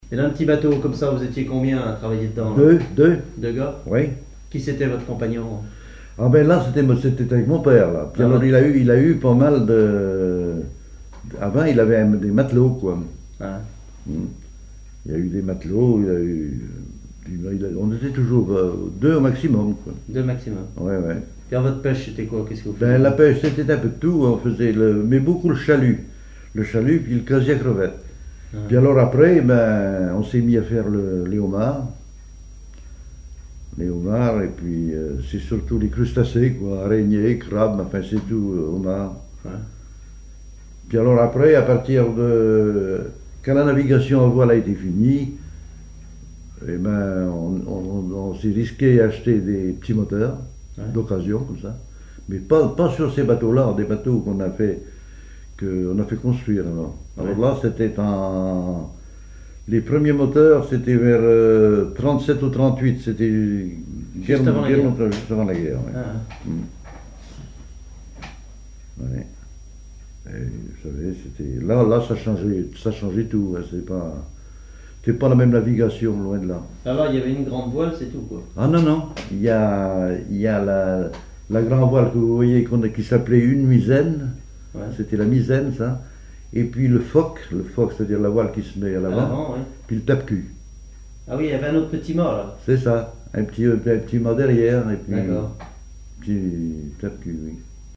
ancien marin pêcheur, charpentier naval
Témoignage